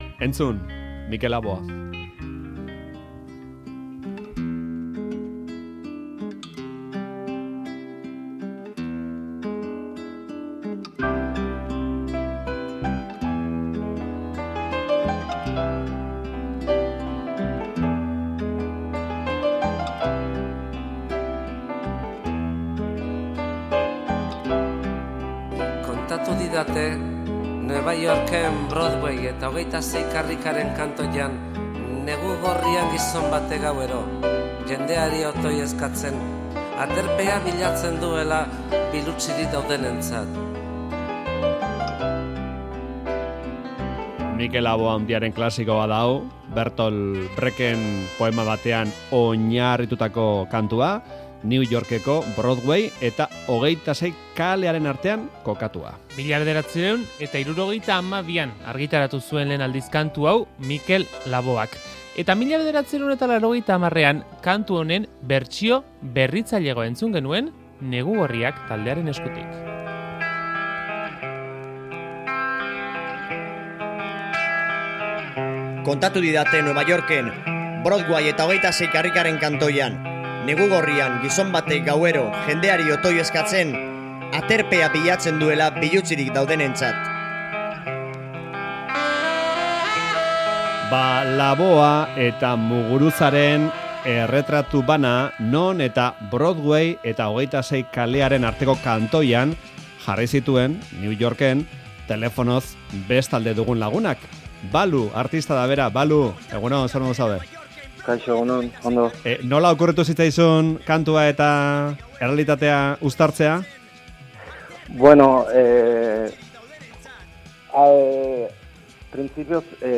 Faktorian elkarrizketatu dugu.